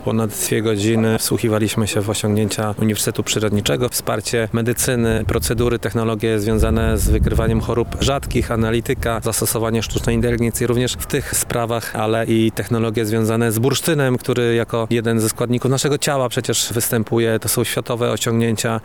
Dzisiaj (22.04) w murach Uniwersytetu Przyrodniczego odbyły się spotkania pomiędzy przedstawicielami uczelni a członkami sejmowej Komisji Cyfryzacji, Innowacyjności i Nowoczesnych Technologii.
Bartłomiej Pejo 1 – mówi poseł Bartłomiej Pejo, przewodniczący Komisji Cyfryzacji, Innowacyjności i Nowoczesnych Technologii.